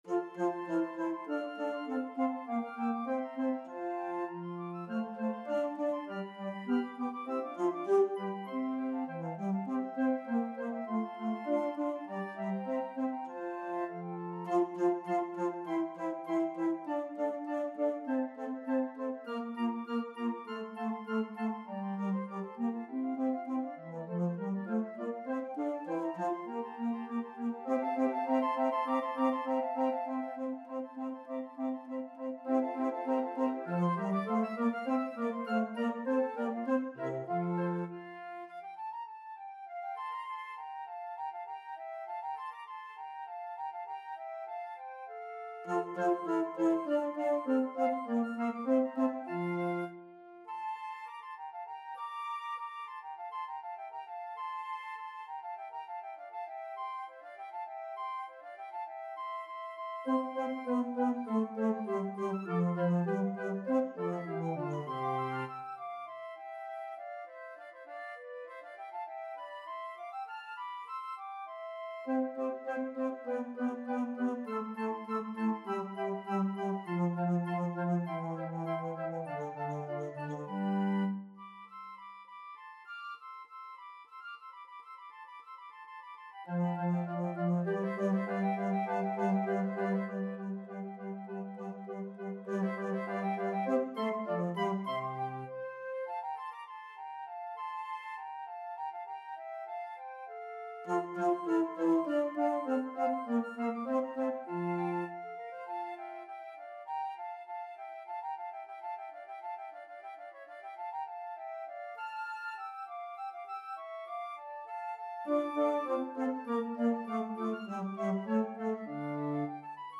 Basfluit | Contrabasfluit (dezelfde stem)
bas_en_contra_Arrival_of_the_Queen_of_Sheba.mp3